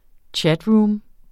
Udtale [ ˈtjadˌɹuːm ]